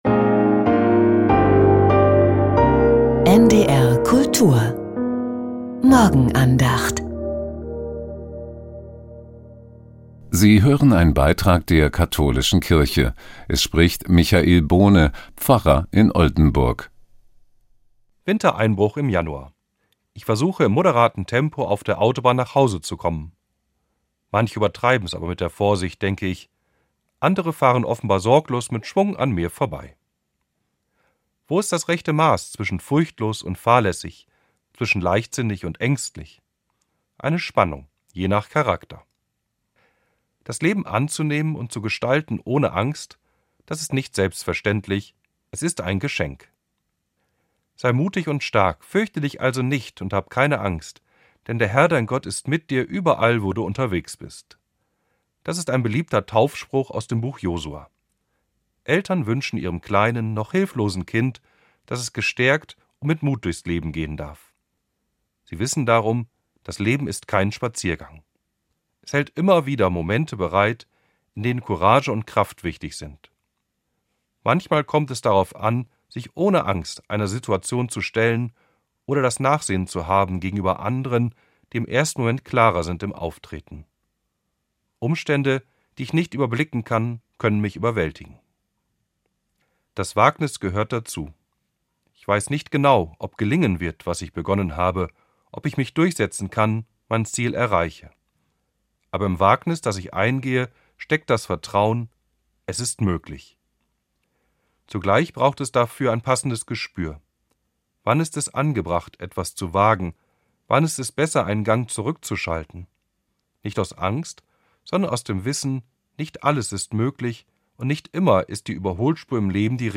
Morgenandacht